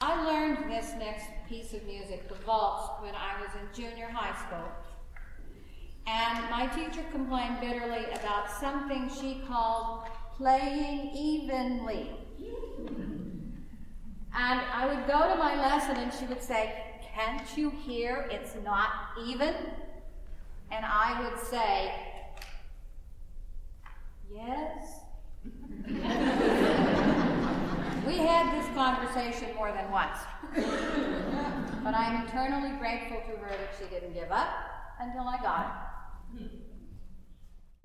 The selections below are from a concert I played called "The Essential Pianist".